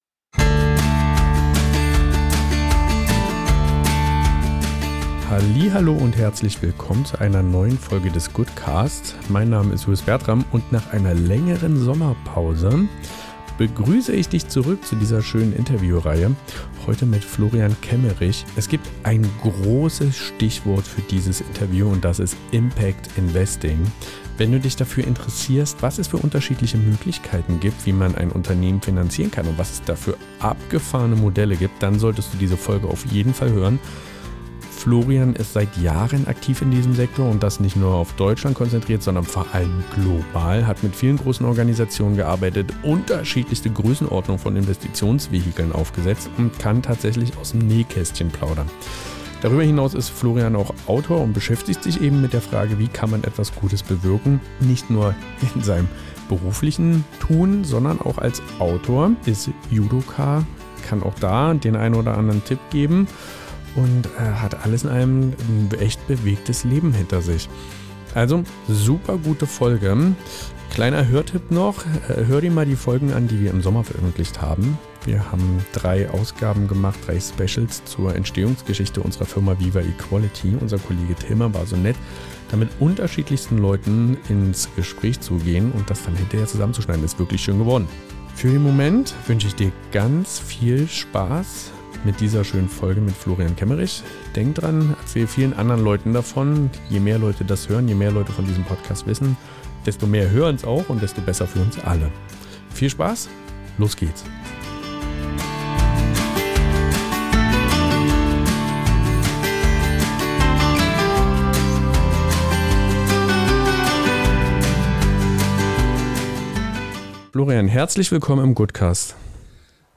Ein offenes und herzliches Gespräch, das inspiriert, berührt und Lust macht, selbst neue Perspektiven einzunehmen.